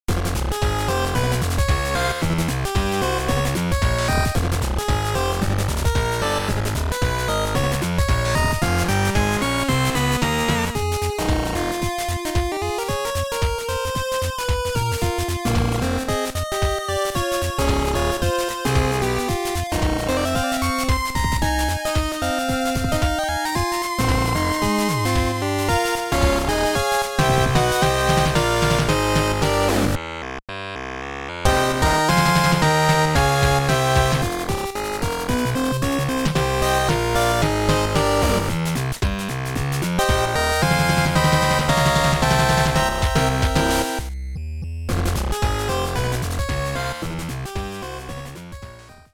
Gameplay theme